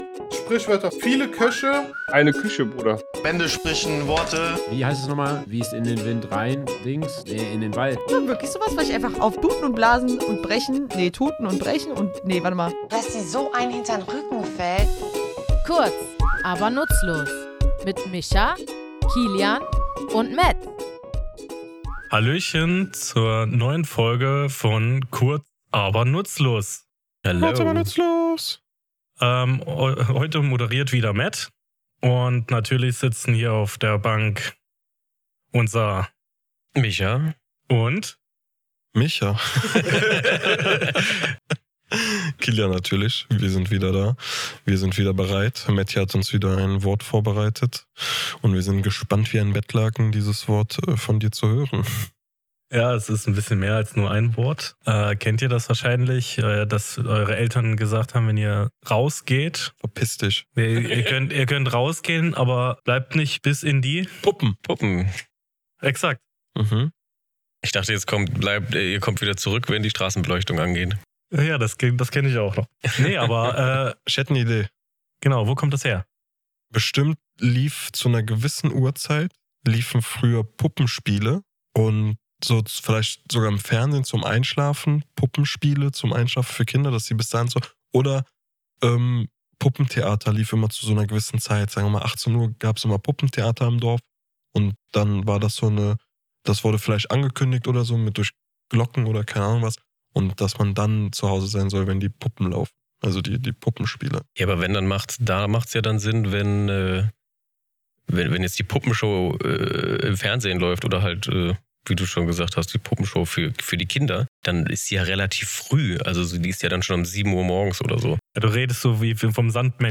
Woher stammt diese kuriose Redewendung, und was haben Puppen damit zu tun? Wir, drei tätowierende Sprachliebhaber, plaudern in unserem Tattoostudio über die Ursprünge, Bedeutungen und den Charme dieser Redensart.